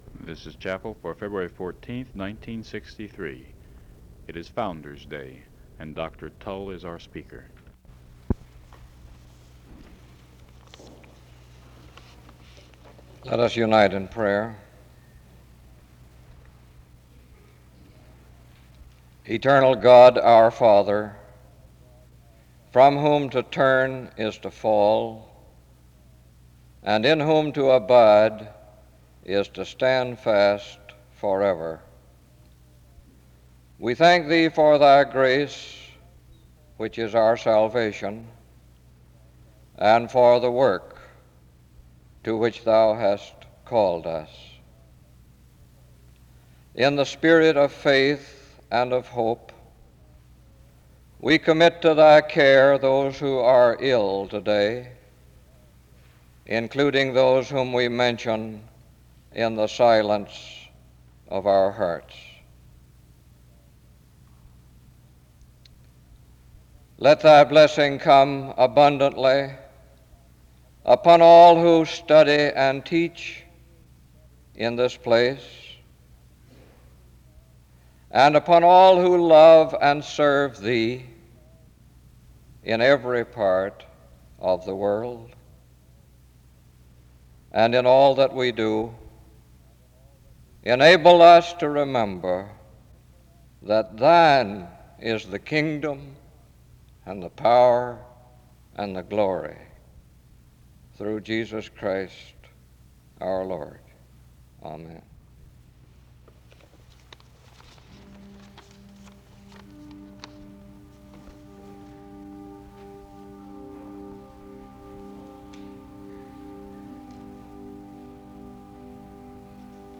The service begins with prayer from 0:00-1:45. There are opening announcements and an introduction to the speaker from 2:00-4:38. The choir performs a song from 4:40-8:36.
A closing prayer is made from 43:49-44:28.